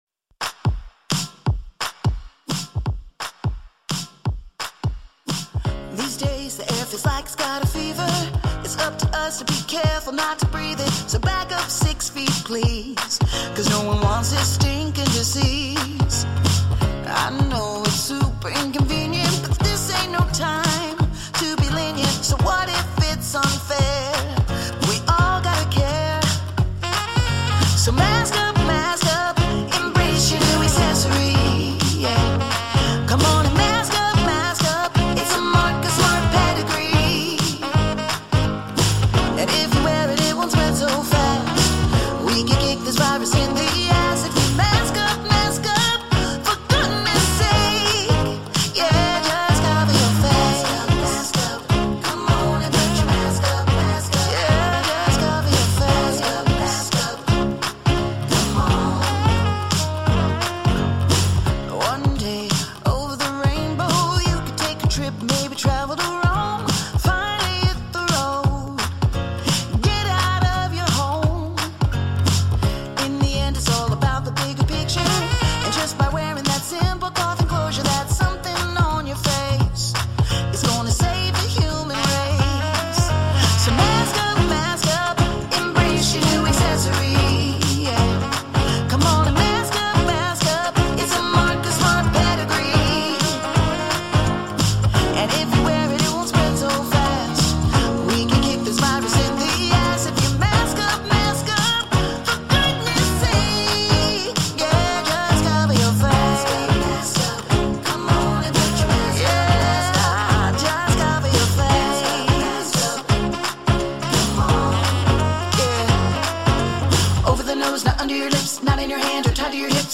A musical PSA that masks are our friends!